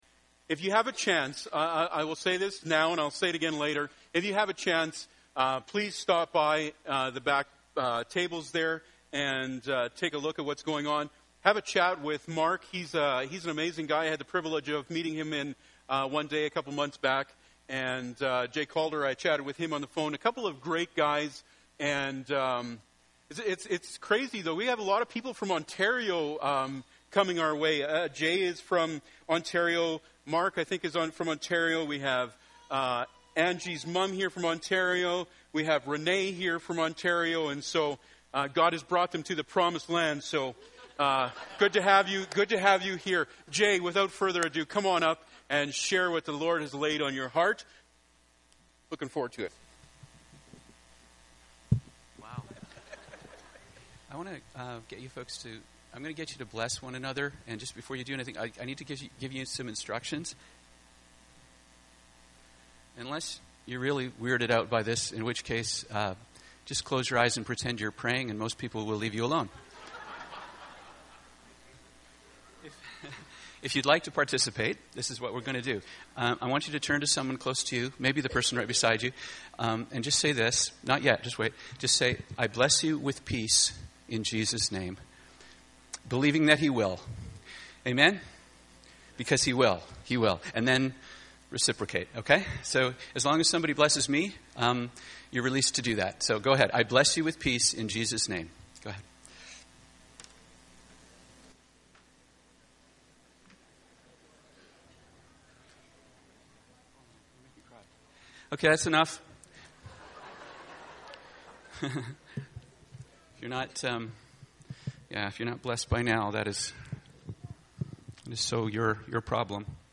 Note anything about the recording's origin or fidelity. Series: Guest Preachers Service Type: Sunday Morning